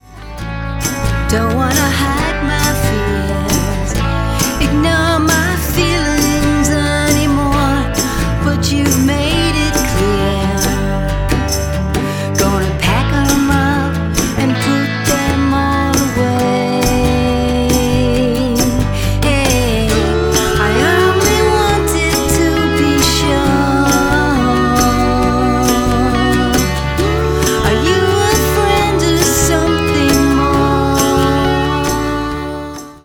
acoustic live in-the-studio